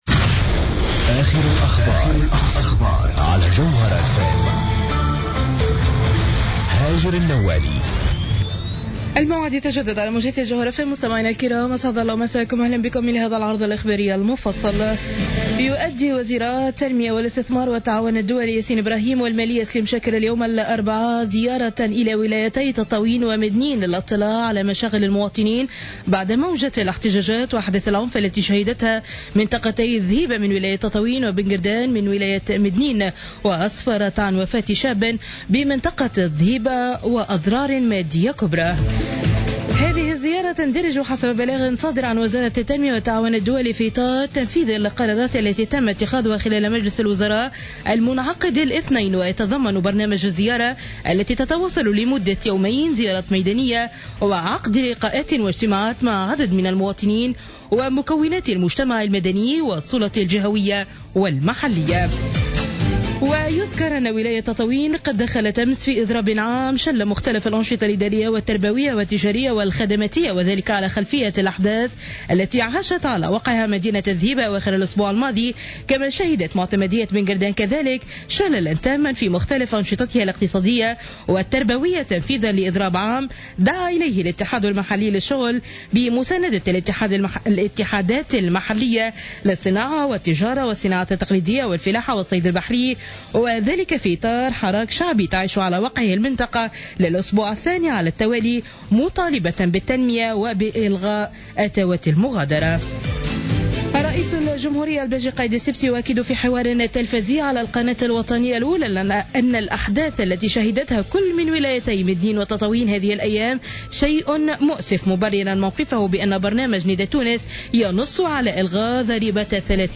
نشرة أخبار منتصف الليل ليوم الاربعاء 11 فيفري 2015